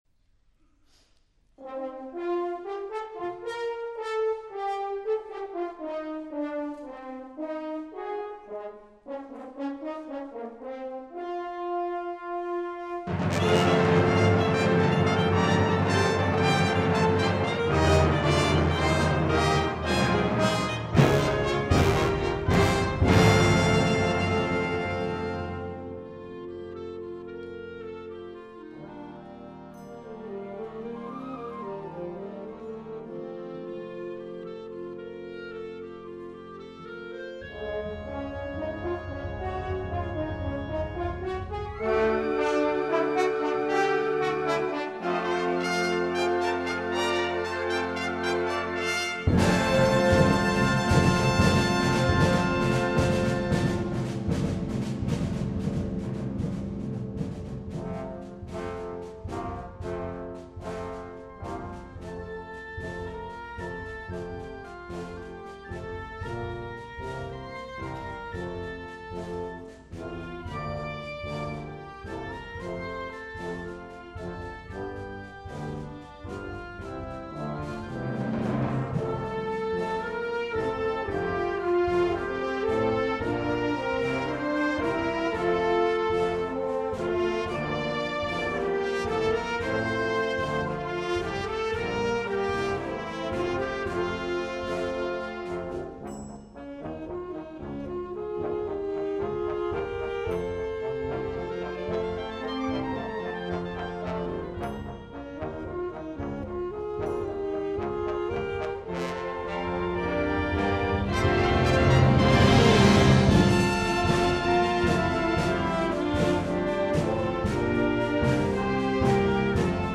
2012年11月18日 新湊地域吹奏楽フェスティバル